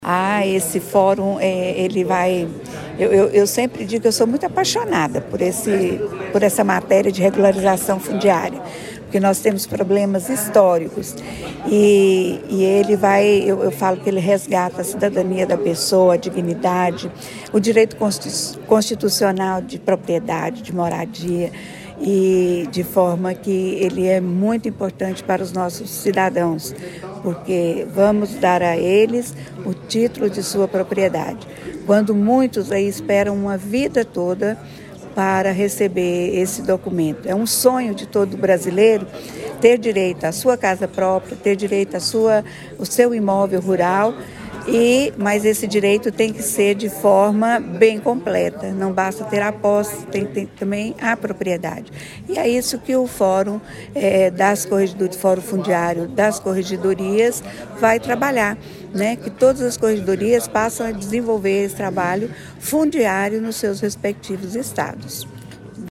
A Desembargadora Etelvina Maria Sampaio Felipe, Presidente do Colégio Permanente de Corregedores-Gerais dos Tribunais de Justiça do Brasil (Ccoge) e Corregedora-Geral de Tocantins, fez questão de destacar que a realização do Fórum é um resgate da cidadania e da dignidade.